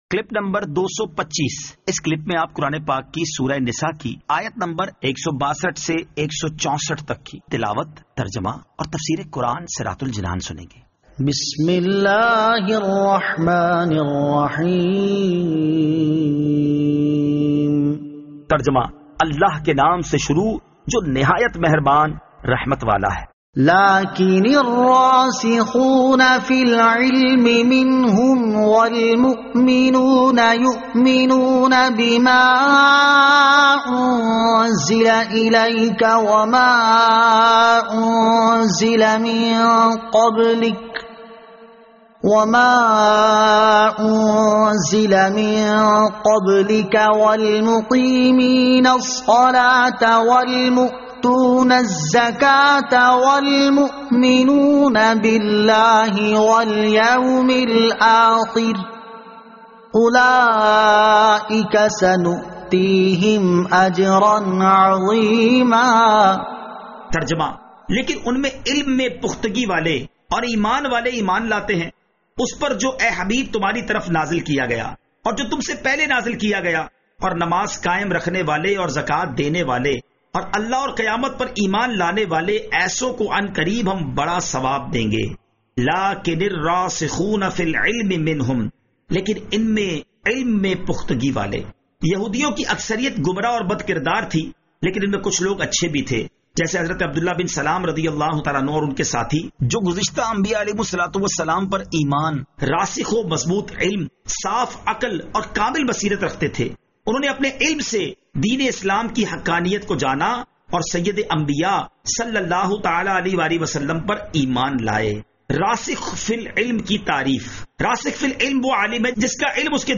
Surah An-Nisa Ayat 162 To 164 Tilawat , Tarjama , Tafseer
2020 MP3 MP4 MP4 Share سُوَّرۃُ النِّسَاء آیت 162 تا 164 تلاوت ، ترجمہ ، تفسیر ۔